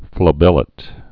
(flə-bĕlĭt, flăbə-lāt) also fla·bel·li·form (flə-bĕlə-fôrm)